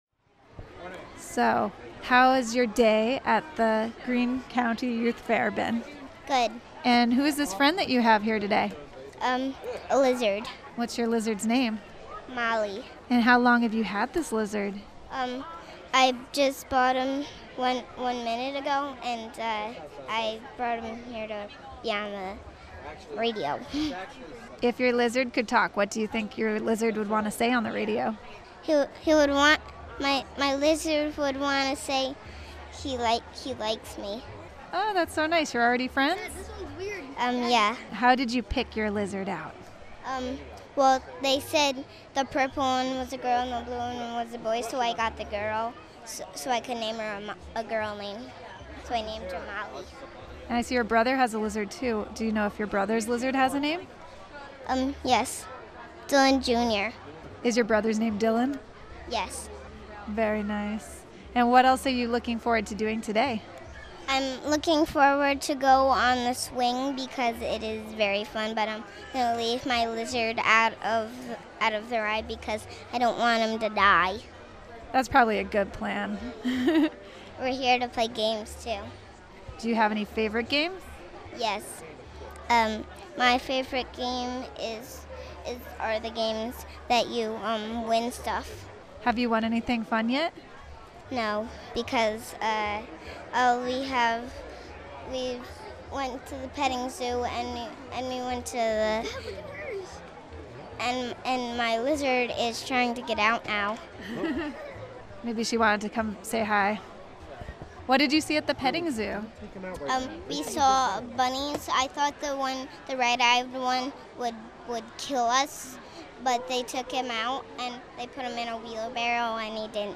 Wave Farm | Molly the Lizard at the Greene County Youth Fair.
Molly the Lizard at the Greene County Youth Fair. (Audio)
GreeneCountyYouthFairMollyTheLizard.mp3